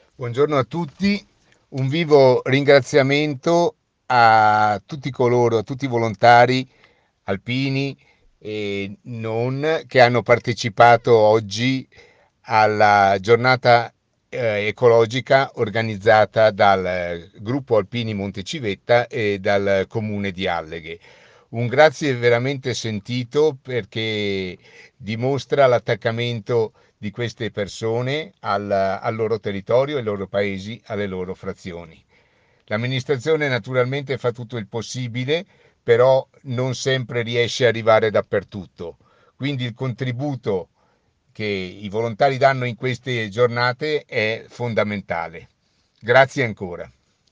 IL SINDACO DI ALLEGHE DANILO DE TONI